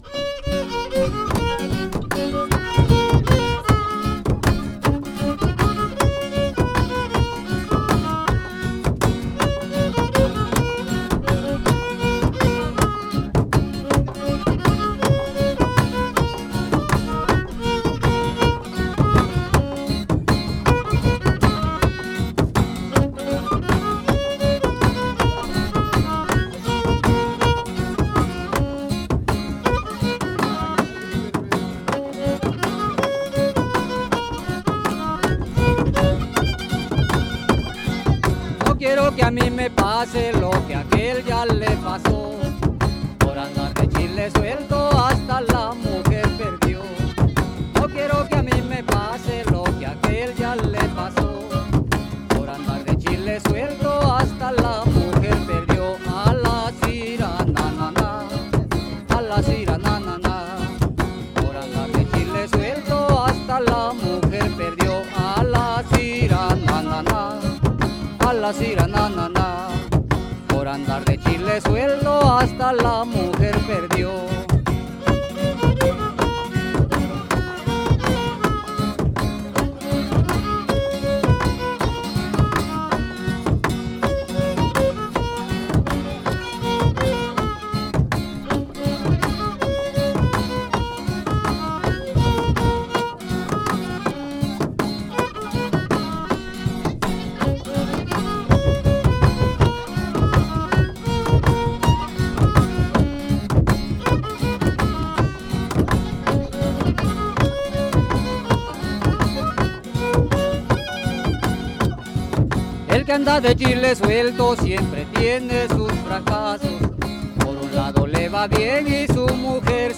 Versos, música y baile de Artesa de la Costa Chica: San Nicolás, Guerrero y el Ciruelo, Oaxaca